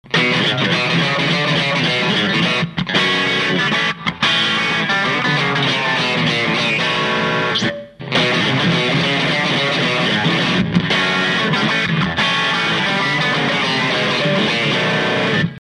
0.082はファット過ぎますし0.056だと変化が地味すぎるので0.068μＦに、とりあえずは